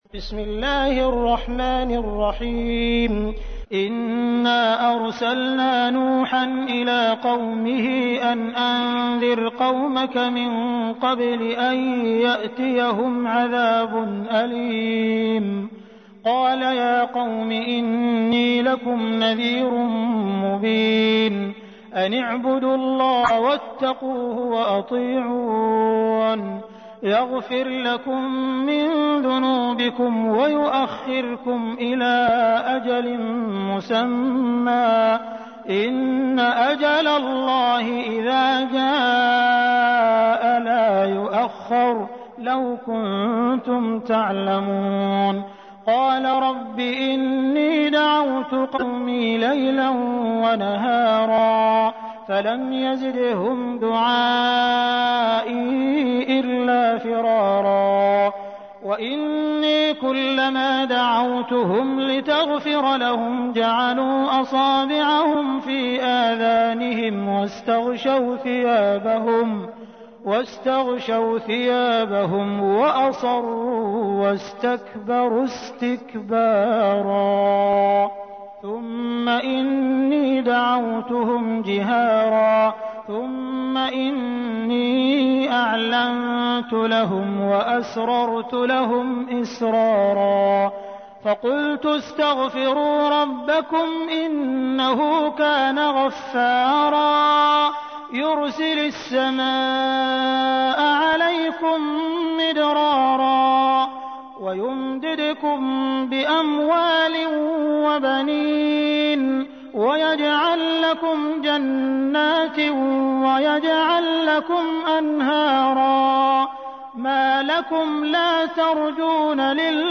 تحميل : 71. سورة نوح / القارئ عبد الرحمن السديس / القرآن الكريم / موقع يا حسين